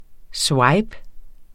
Udtale [ ˈswɑjb ]